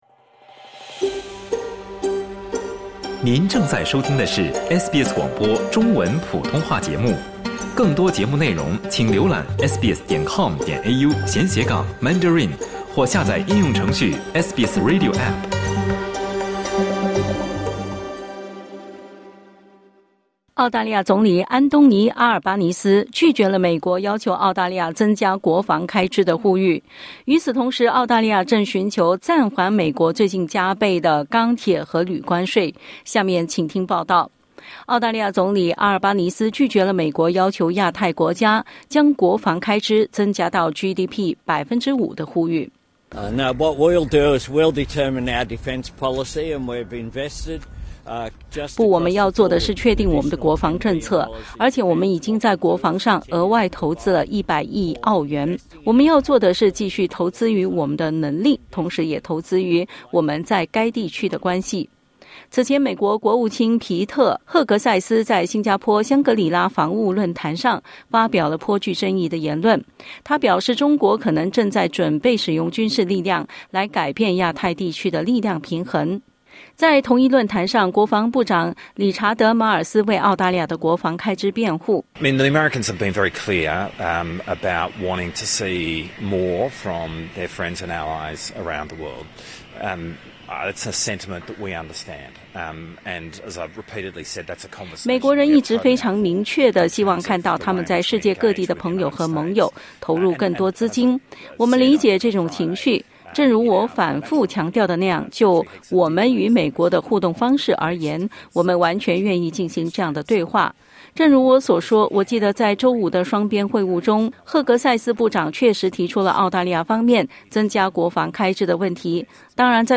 澳大利亚总理安东尼·阿尔巴尼斯 (Anthony Albanese) 拒绝了美国要求澳大利亚增加国防开支的呼吁。 与此同时，澳大利亚正寻求暂缓美国最近加倍的钢铁和铝关税。 (点击音频收听详细报道)